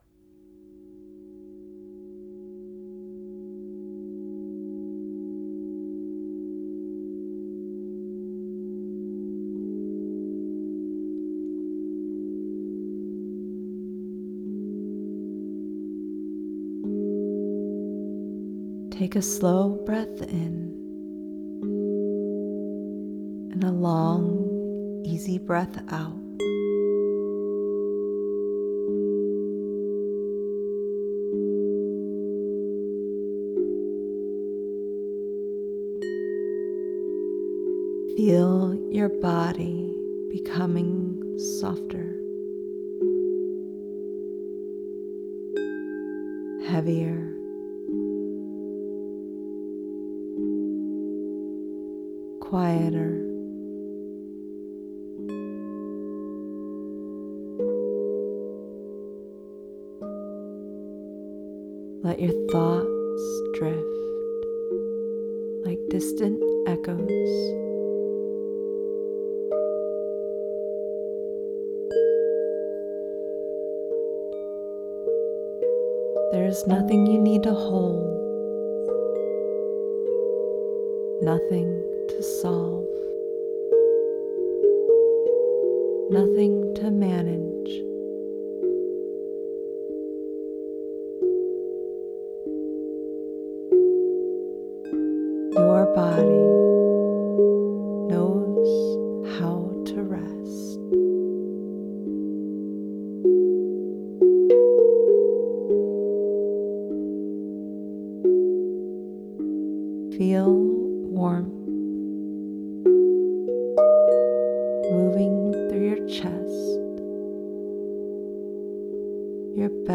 Om Mani Padme Hum: Gentle Relaxation for Bedtime